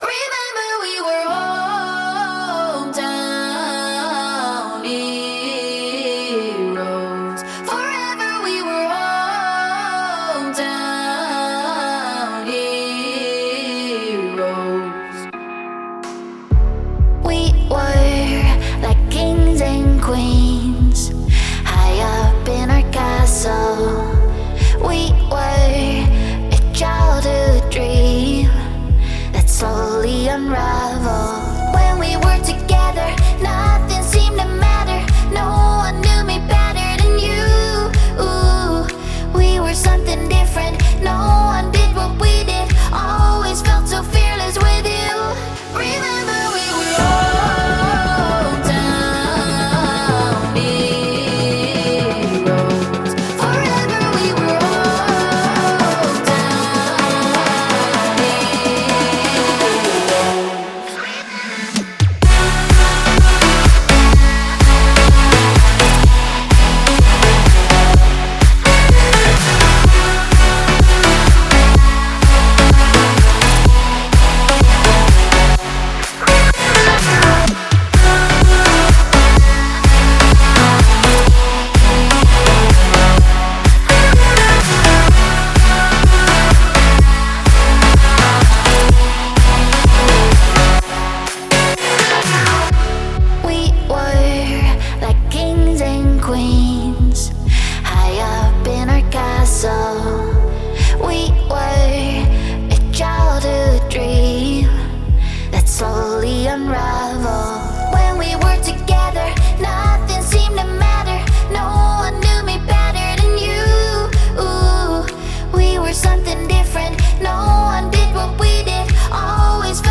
это энергичная EDM-композиция